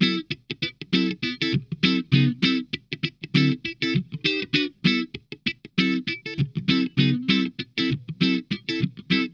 DEEP CHUG 2.wav